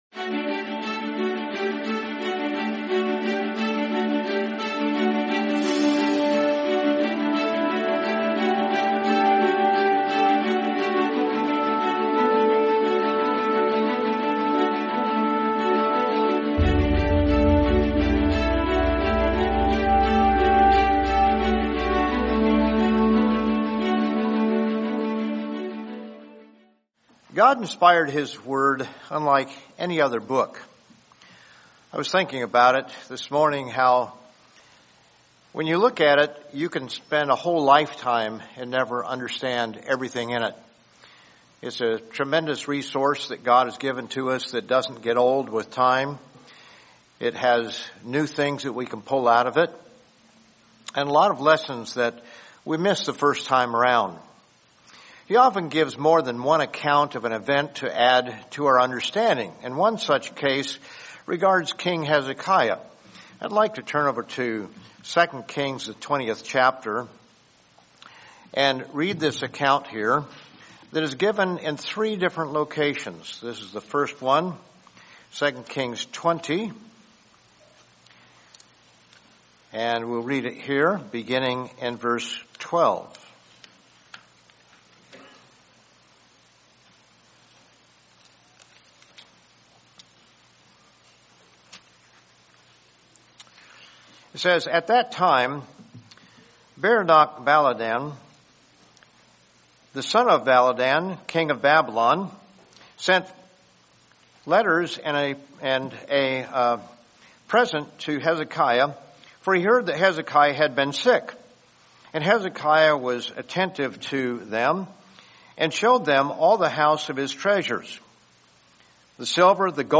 Welcome to the Living Church of God’s audio sermon podcast feed where you will find sermons on topics including Prophecy, Christian Living, Bible Teachings, current National and World News, and trends.